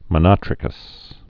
(mə-nŏtrĭ-kəs)